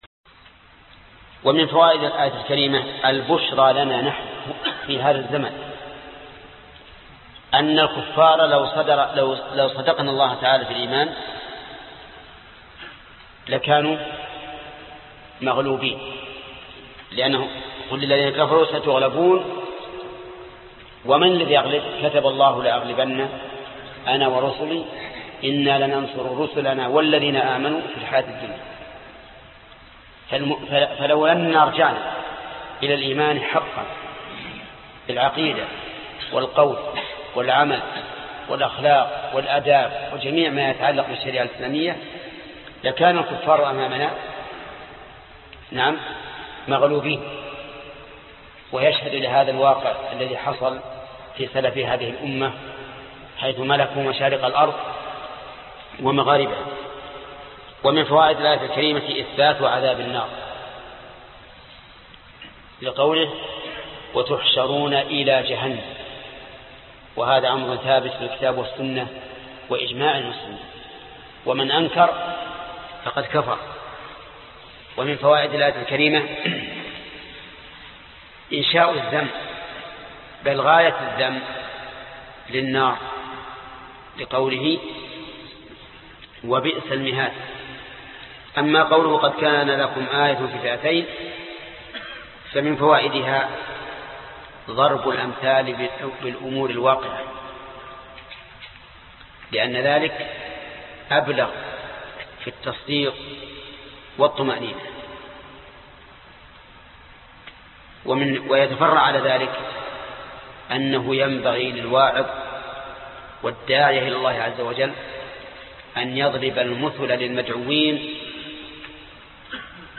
الدرس 23 الآية رقم 14:12 (تفسير سورة آل عمران) - فضيلة الشيخ محمد بن صالح العثيمين رحمه الله